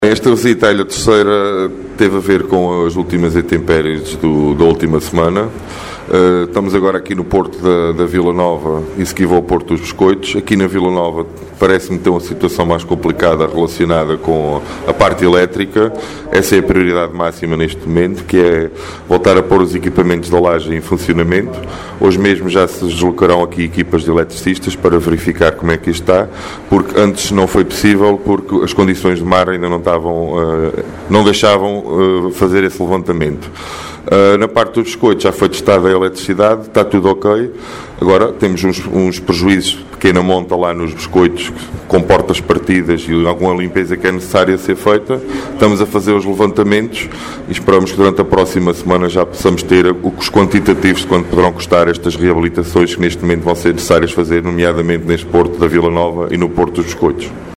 “Essa é a prioridade máxima neste momento, voltar a colocar os equipamentos de alagem em funcionamento”, frisou Luís Costa, durante uma visita aos portos de pesca da ilha Terceira mais afetados pela forte ondulação dos últimos dias para se inteirar dos prejuízos.